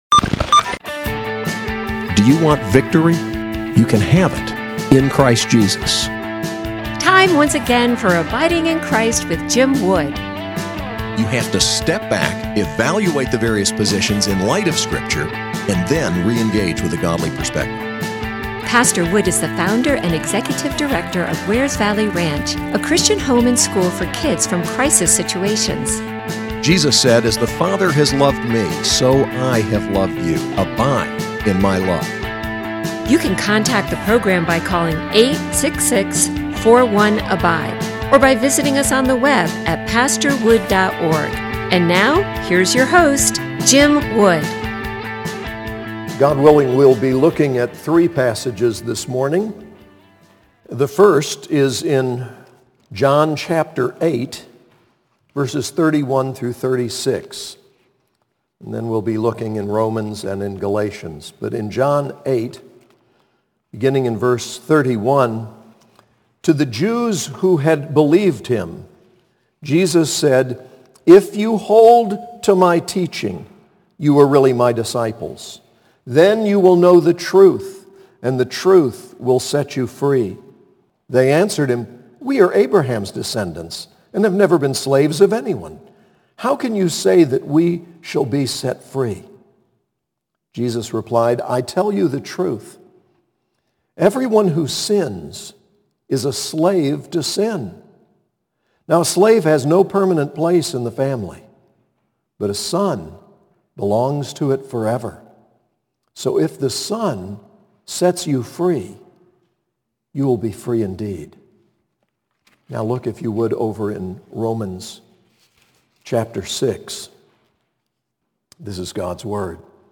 SAS Chapel: John 8:31-36, Romans 6, Galatians 5:13-26